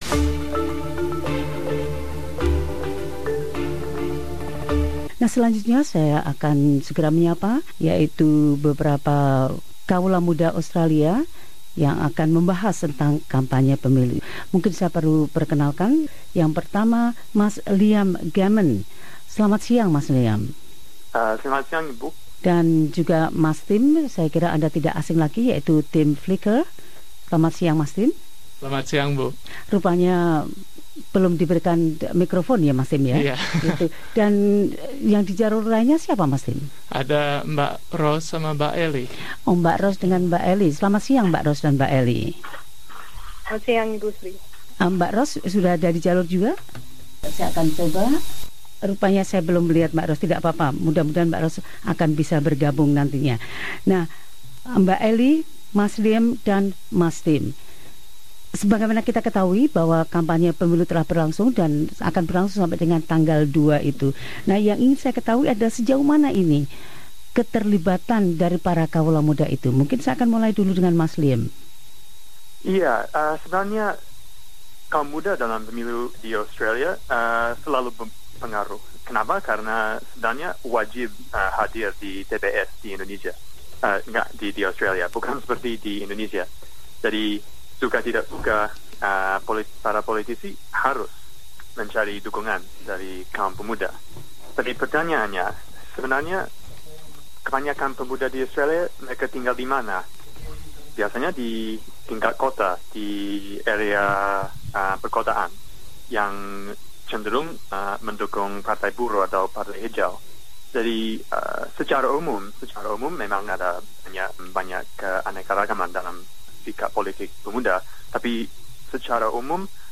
Empat kawula muda Australia yang dapat berbicara bahasa Indonesia sebagai bahasa asing, mengemukakan berbagai pendapat mereka yang berbeda serta menjelaskan tingkat keterlibatan para kawula muda dalam pemilu 2016.